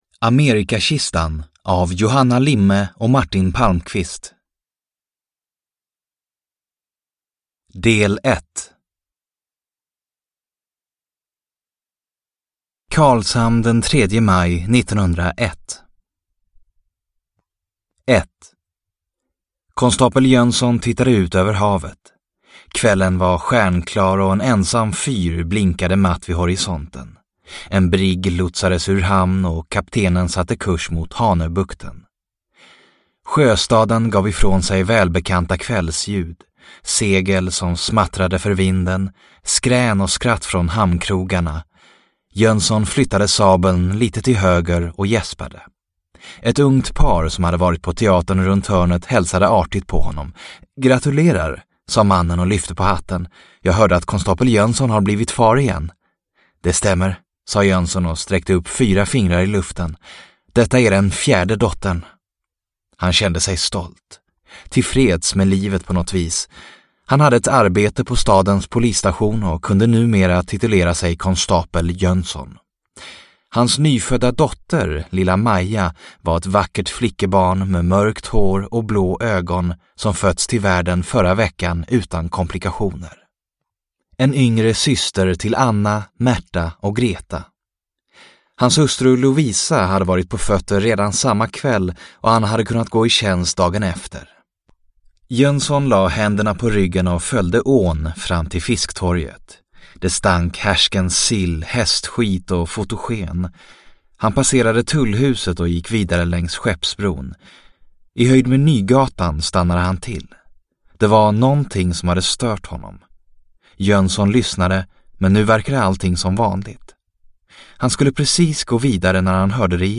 Amerikakistan – Ljudbok – Laddas ner